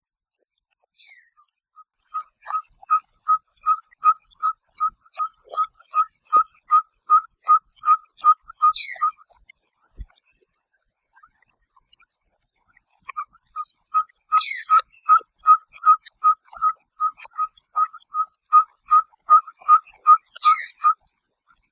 Glaucidium brasilianum brasilianum - Caburé
Llamado: Da una larga serie de llamadas a tres por segundo, repetidas de 10 a 60 veces; estas frases se dan en intervalos de varios segundos.
Canta durante el día y la noche, para atraer pájaros pequeños.
cabure.wav